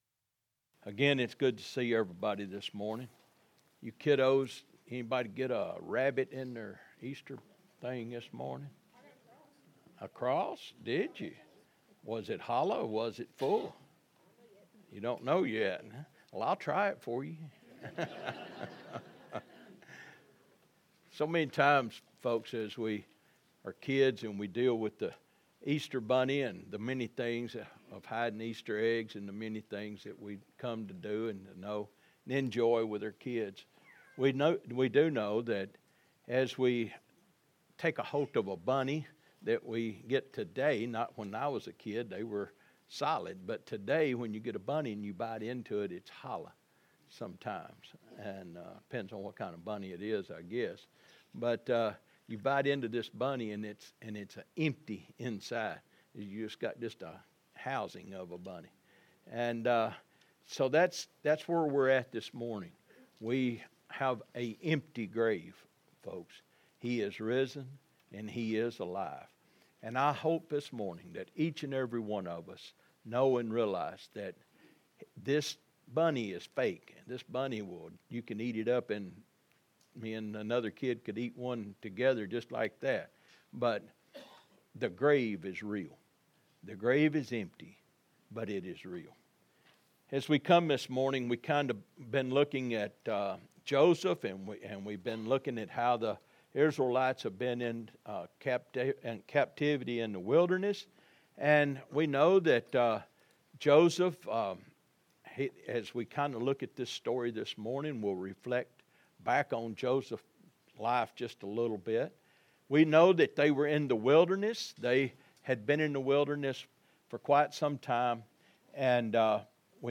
April 5th, 2026 – Easter Sunday – GraceLife Church of Paris